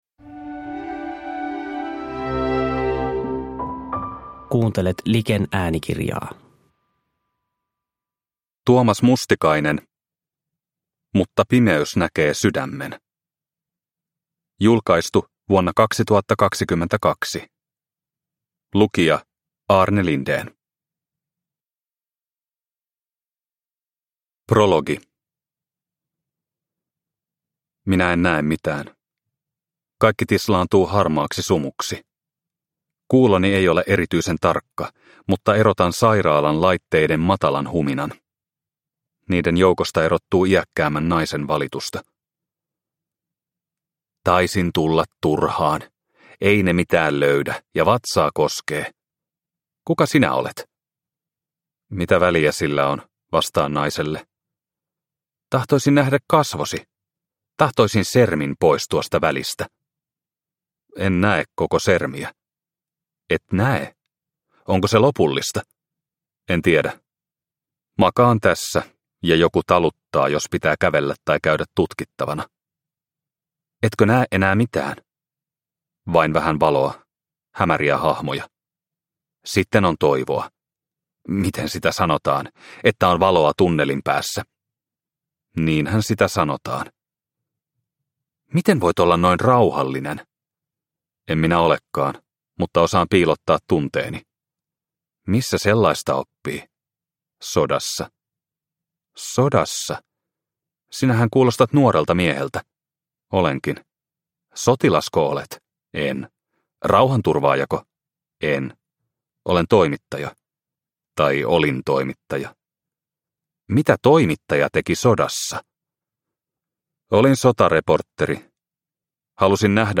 Mutta pimeys näkee sydämen – Ljudbok – Laddas ner